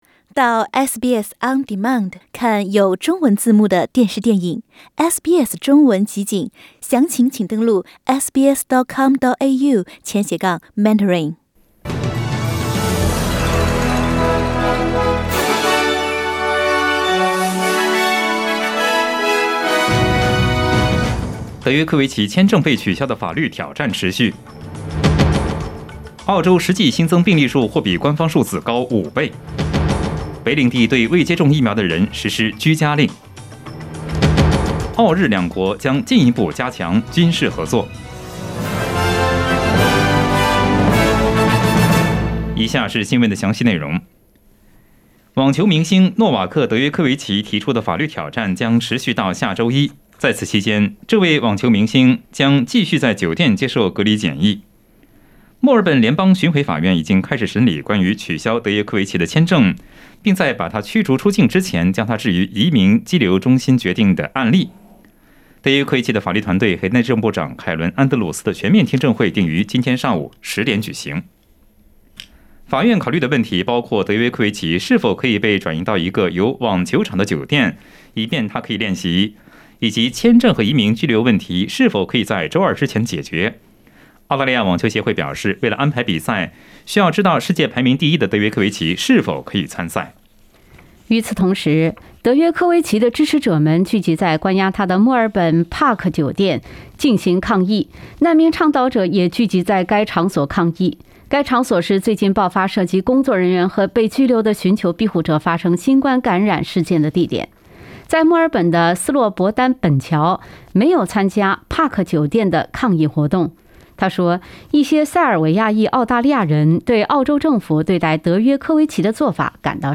SBS早新闻（1月7日）
SBS Mandarin morning news Source: Getty Images